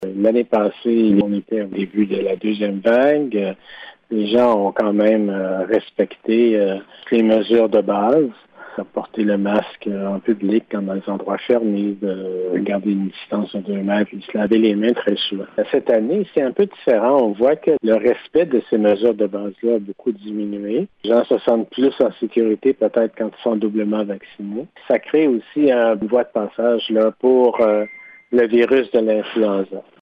Le directeur de la Santé publique explique les raisons entourant l’apparition  de cas d’influenza en 2021 en comparaison avec 2020.